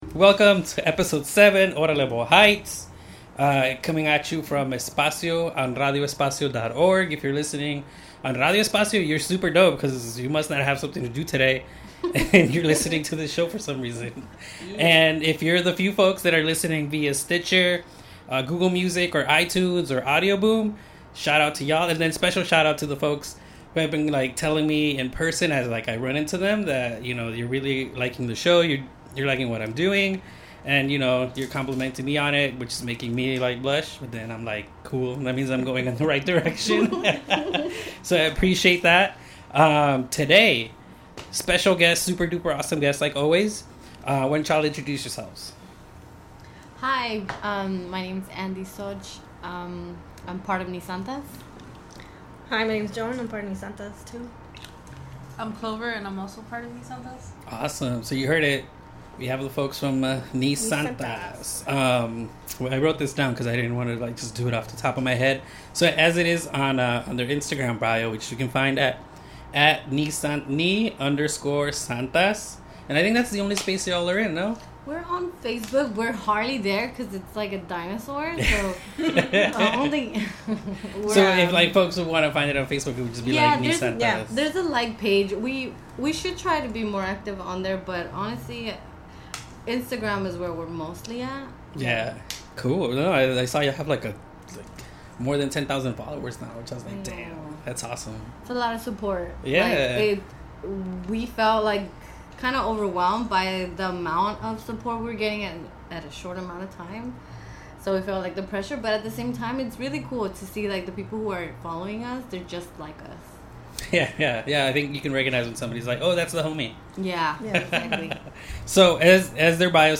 Órale Boyle Heights is recorded at Espacio 1839 in Boyle Heights in front of a live studio audience.